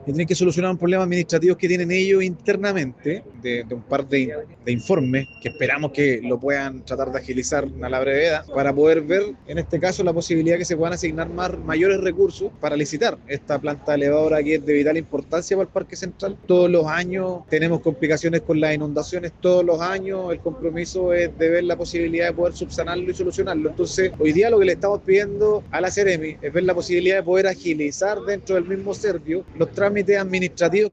El alcalde de Hualpén, Miguel Rivera, indicó que algunos vecinos llevan más de 20 años esperando, y que el retraso sería por un proceso administrativo.